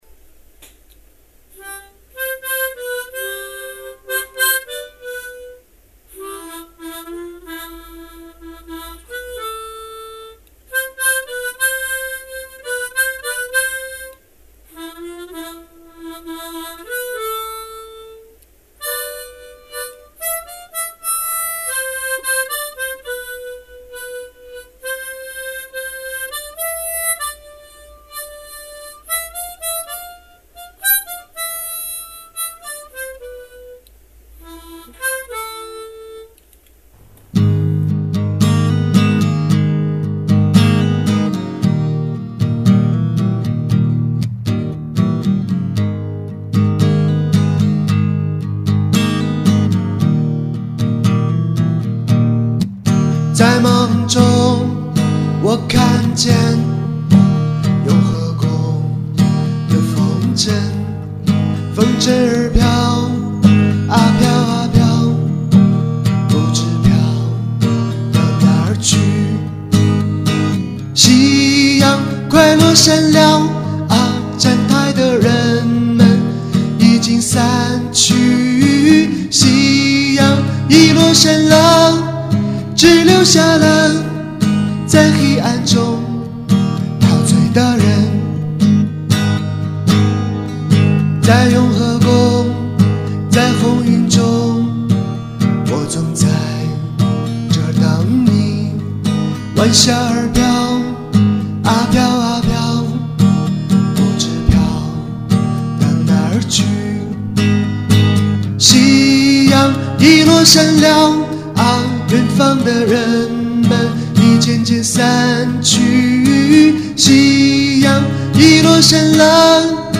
录音并不完美，不过，其实我喜欢的也不是完美，而是唱歌时那种最真实的心情……希望你有感觉